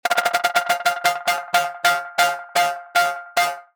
Vortex_OS_Rolls_3_F
Vortex_OS_Rolls_3_F.mp3